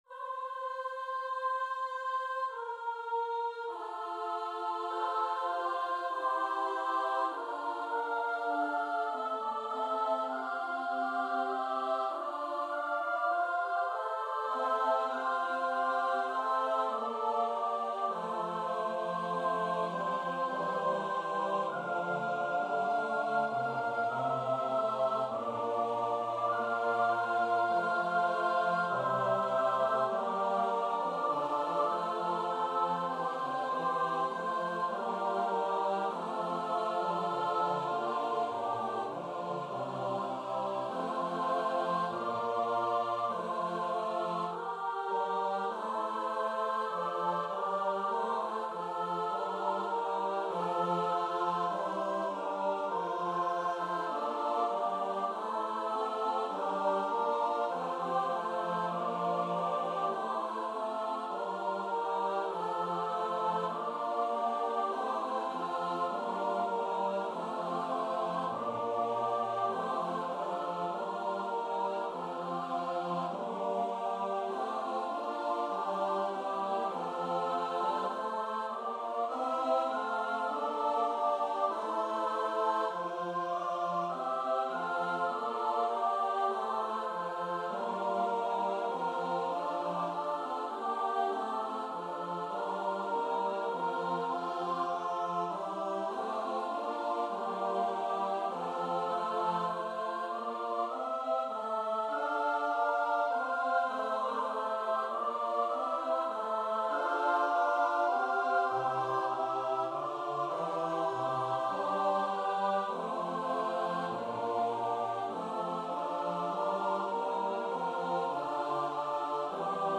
Free Sheet music for Choir (SSATB)
F major (Sounding Pitch) (View more F major Music for Choir )
4/4 (View more 4/4 Music)
Choir  (View more Intermediate Choir Music)
Classical (View more Classical Choir Music)